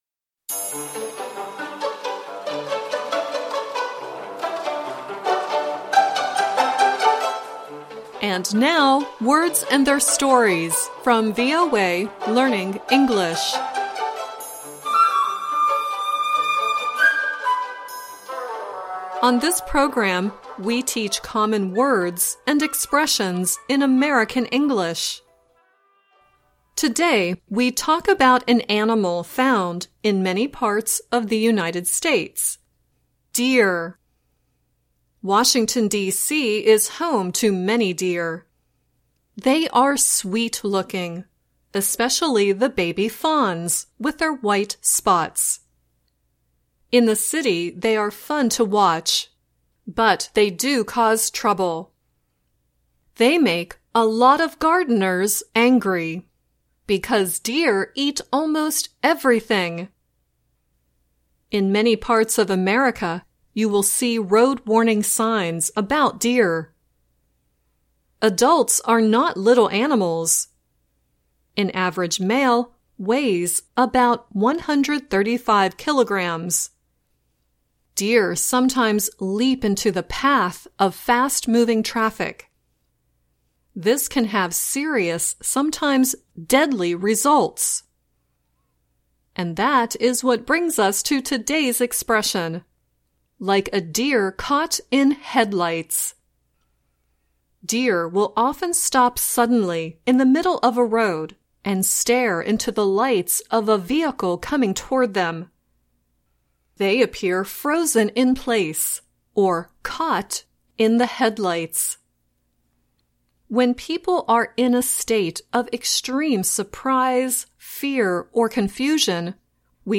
The song at the end is Sia singing "Deer in Headlights."